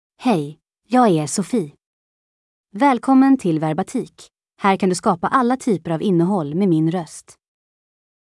FemaleSwedish (Sweden)
Sofie is a female AI voice for Swedish (Sweden).
Voice sample
Female
Sofie delivers clear pronunciation with authentic Sweden Swedish intonation, making your content sound professionally produced.